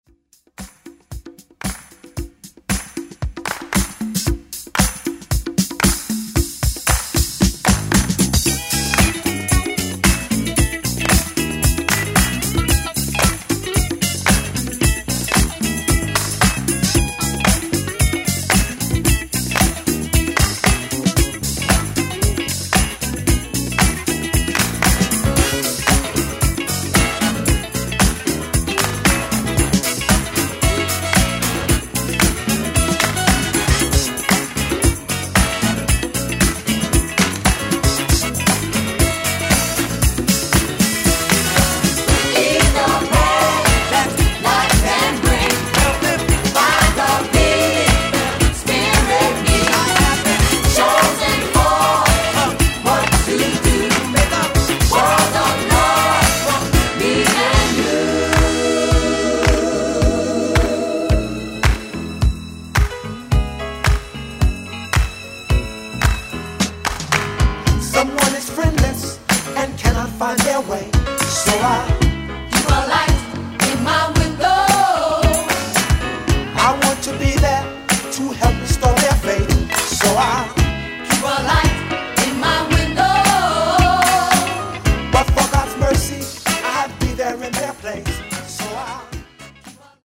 rare gospel disco classic
Disco Funk